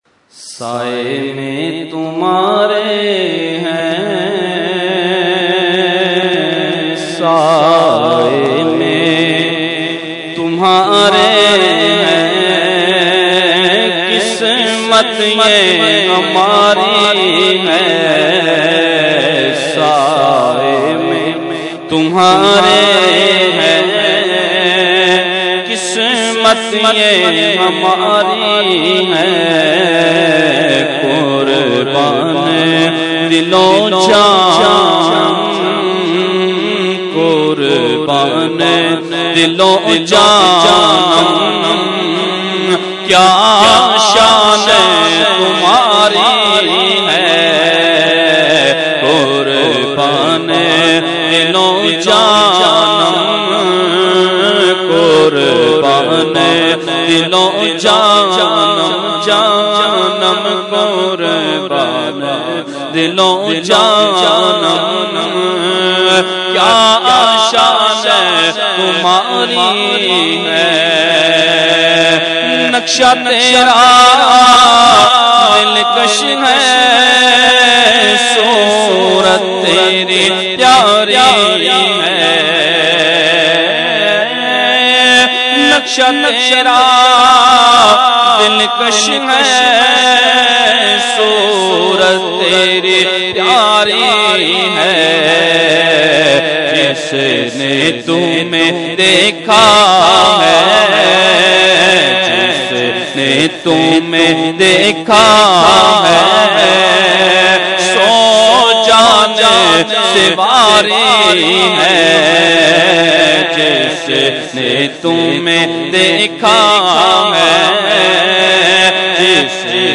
Category : Naat | Language : UrduEvent : Urs Ashraful Mashaikh 2014